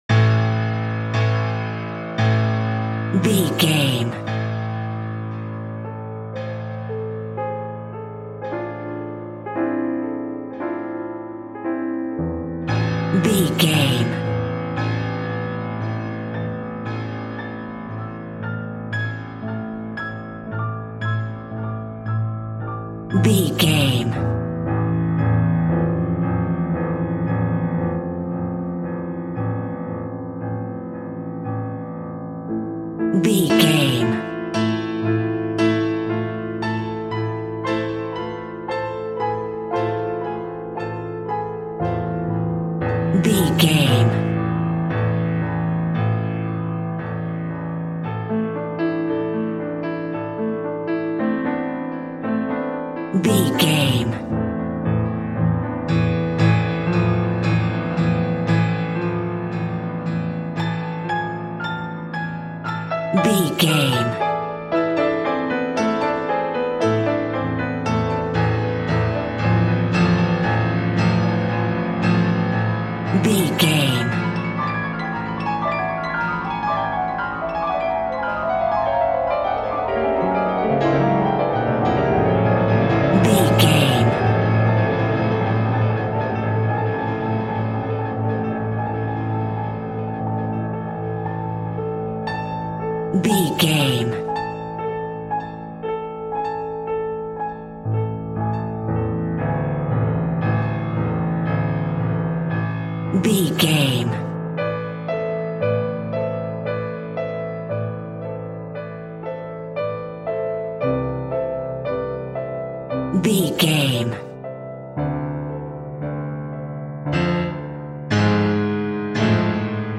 Aeolian/Minor
scary
ominous
dark
suspense
haunting
eerie
Horror Ambience
Synth Pads
Synth Ambience